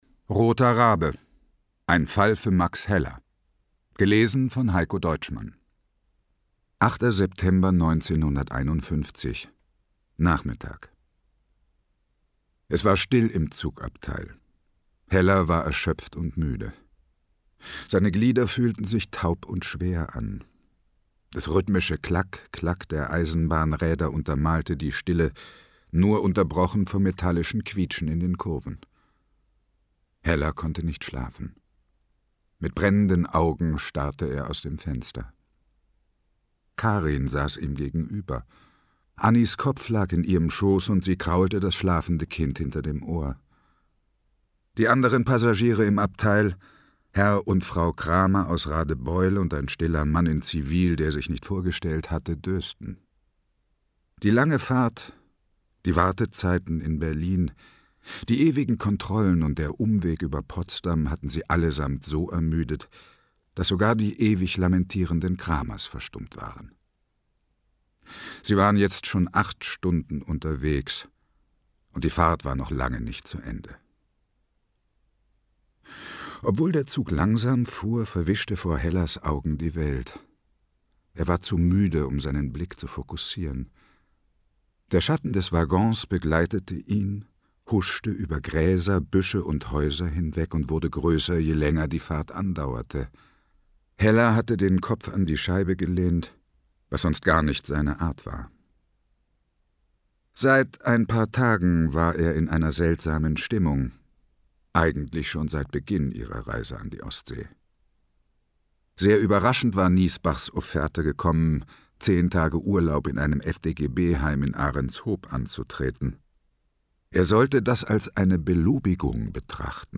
Hörbuch: Roter Rabe.
Roter Rabe. Ein Fall für Max Heller Ungekürzte Lesung mit Heikko Deutschmann
Heikko Deutschmann (Sprecher)